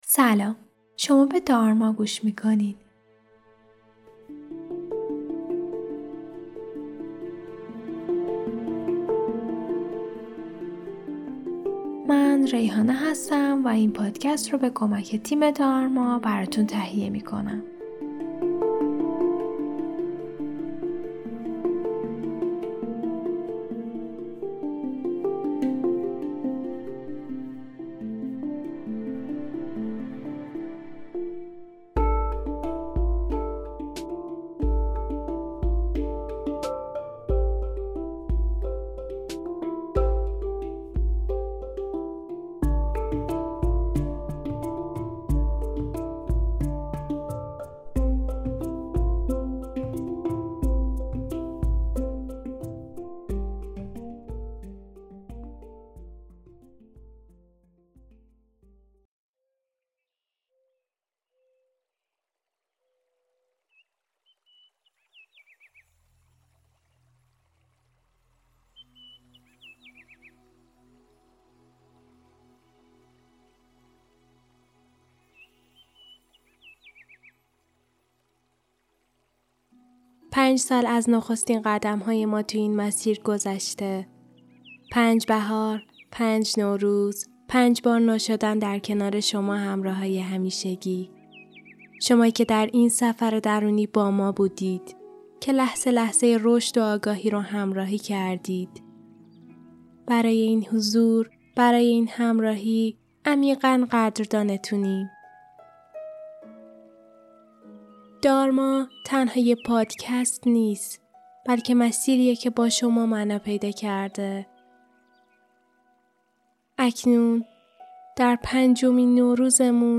نو شدن – مدیتیشن در طبیعت 1404